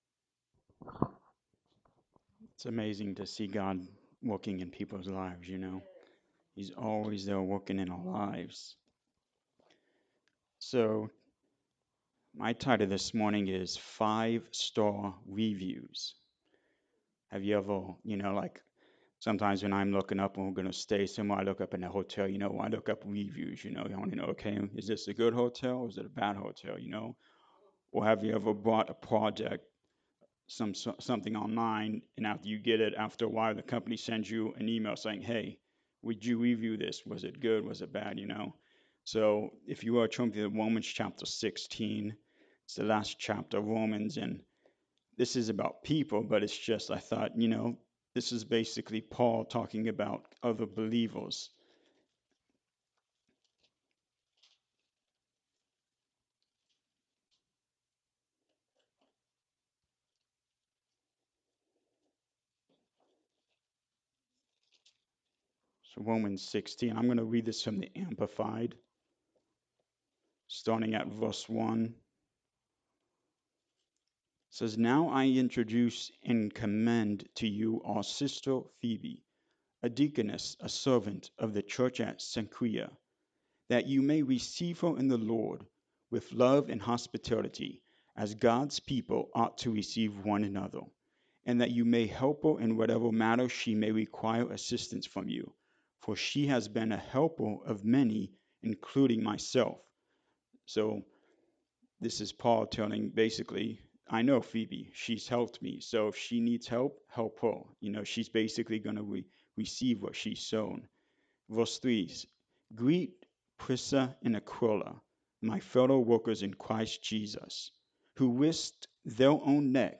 Service Type: Sunday Morning Service